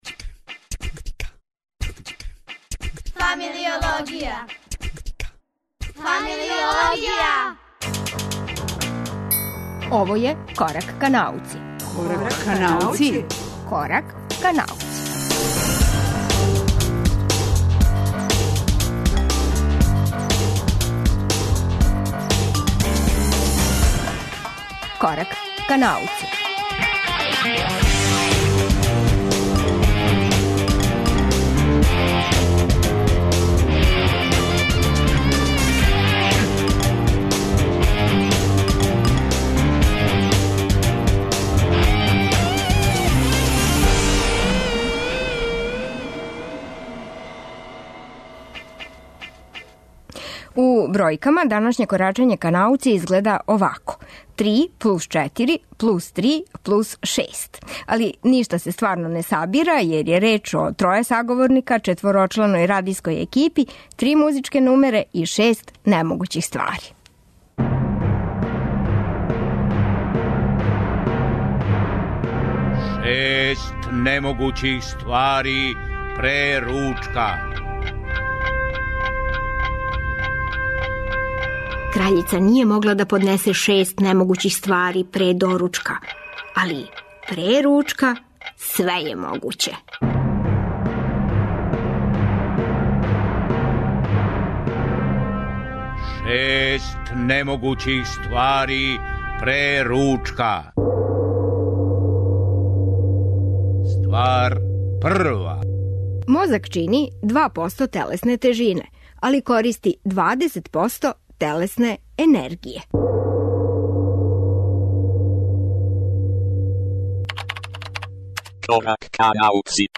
У бројкама данашње корачање ка науци изгледа овако: 3+4+3+6. Али ништа се стварно не сабира, јер је реч о троје саговорника, четворочланој радијској екипи, три музичке нумере и шест немогућих ствари.